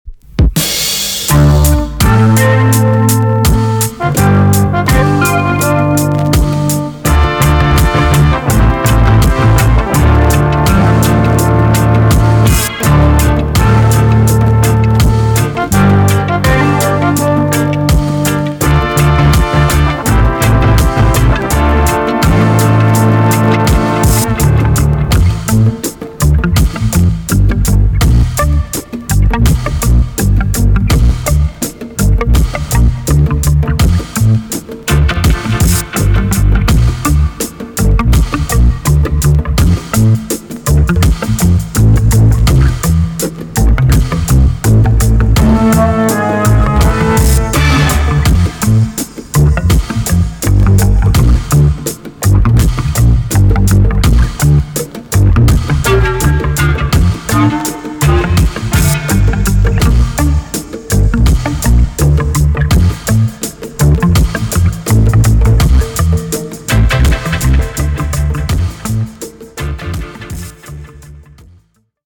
EX- 音はキレイです。